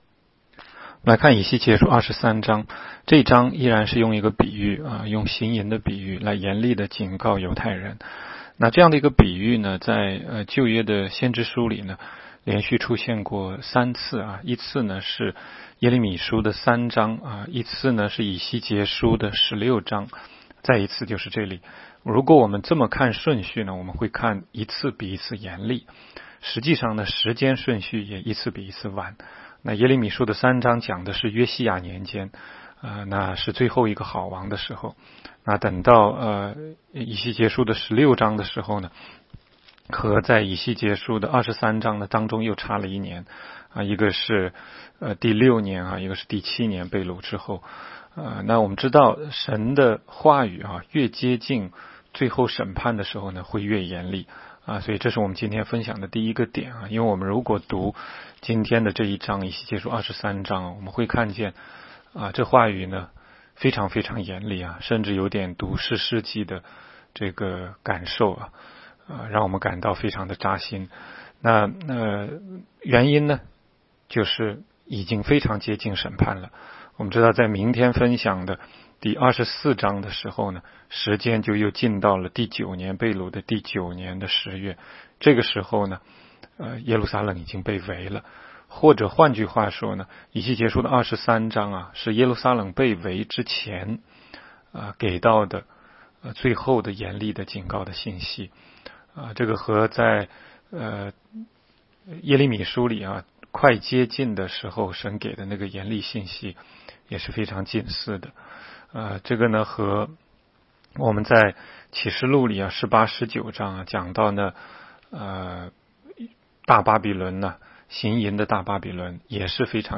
16街讲道录音 - 每日读经 -《以西结书》23章